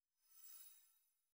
Magic_drawing.wav